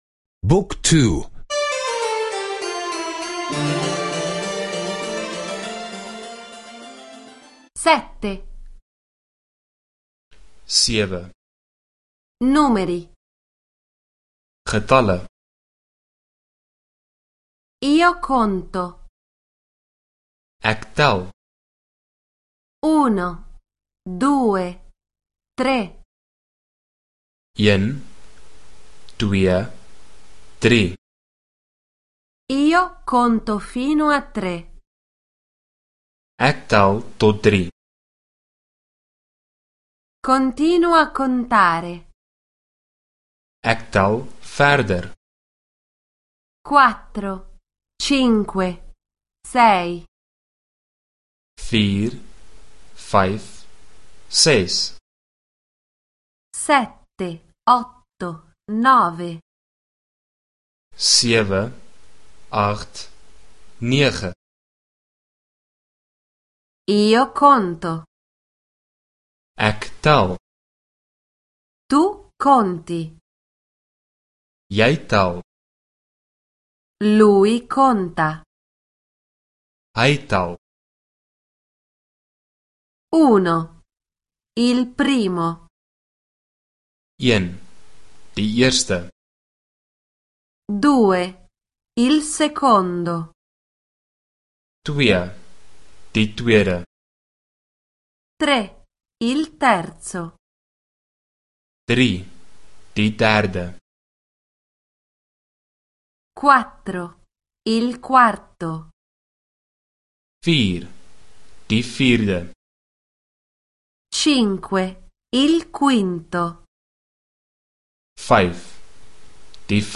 Audio corso afrikaans — ascolta online